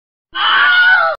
Scream Funny Meme Sound Effect: Unblocked Meme Soundboard